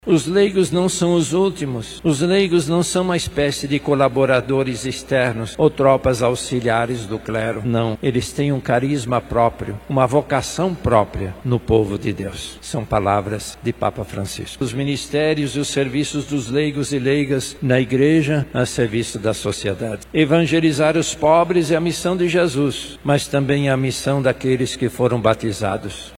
No 21º Domingo do Tempo Comum, durante a homilia celebrada na Catedral Metropolitana de Manaus, o Arcebispo, cardeal Leonardo Steiner, reforçou o valor da participação ativa dos leigos na missão evangelizadora da Igreja.